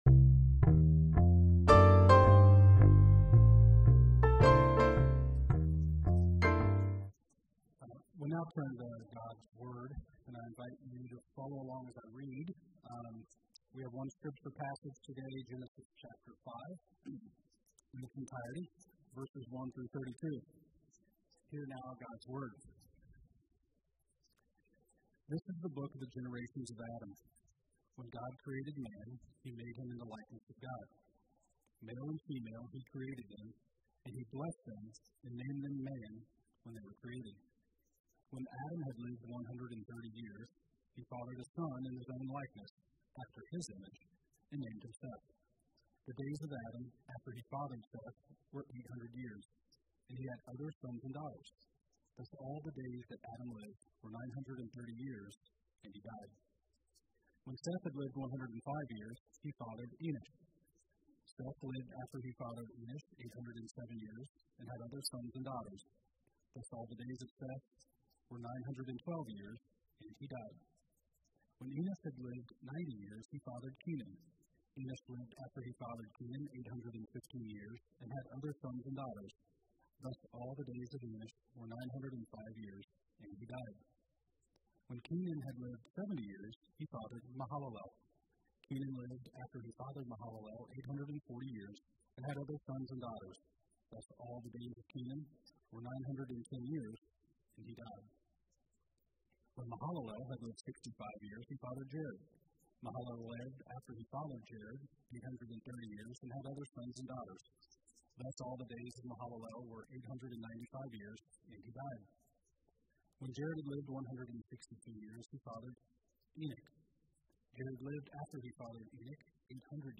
Passage: Genesis 5: 1-32 Service Type: Sunday Worship